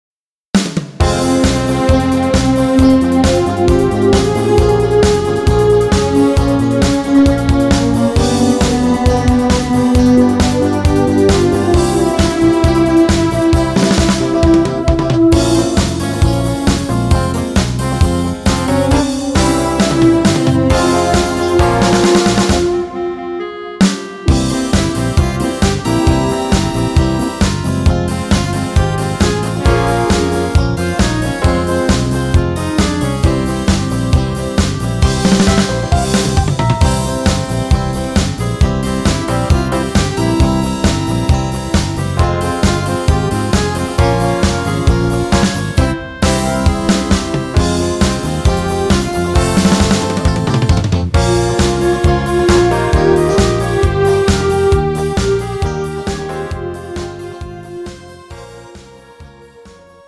음정 -1키 2:47
장르 가요 구분 Pro MR